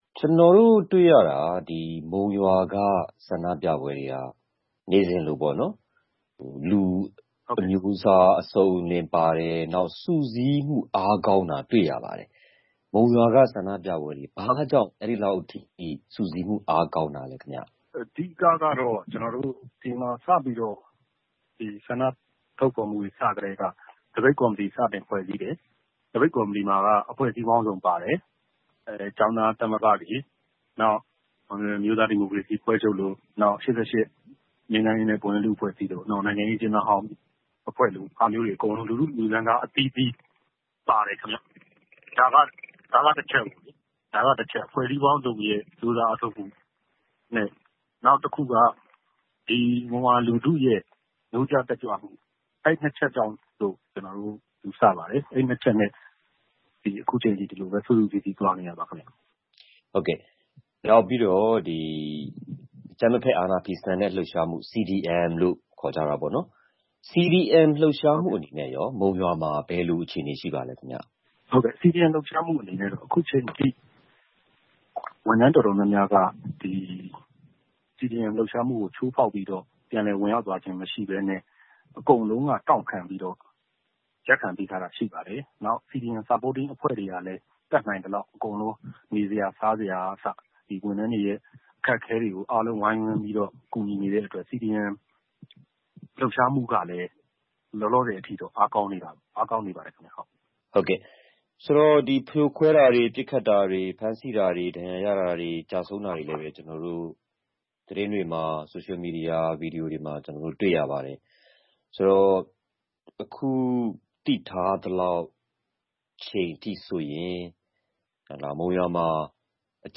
မုံရွာမြို့က ဆန္ဒပြပွဲတွေ စလုပ်ချိန်ကနေ အခုချိန်ထိ ဘာ့ကြောင့် ဒီလောက် စုစည်း အားကောင်းနေသလဲဆိုတာ ဦးဆောင်ပါဝင်သူတစ်ဦးနဲ့ ဆက်သွယ်မေးမြန်းထားပါတယ်။